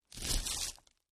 ho_fleshtear_01_hpx
Various parts of human flesh being ripped and torn. Mutilation, Body Dismemberment, Gore Tear, Flesh